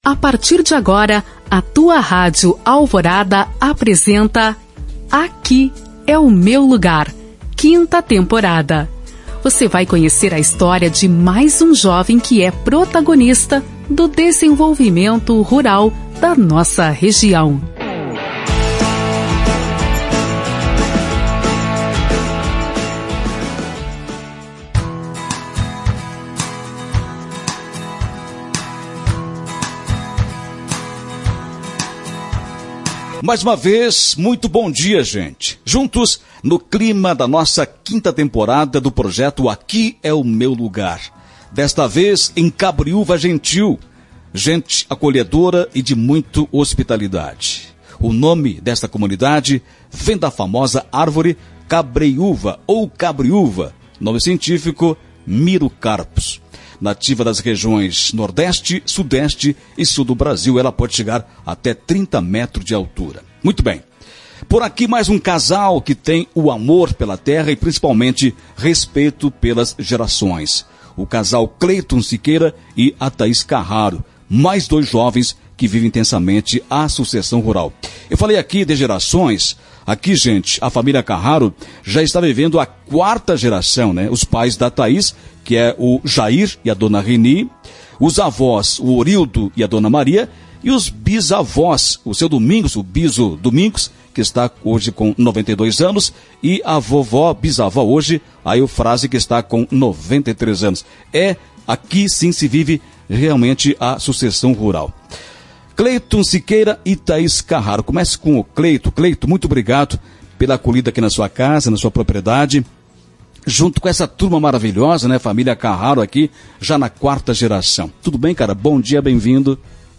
Ouça a reportagem com o casal clicando aqui.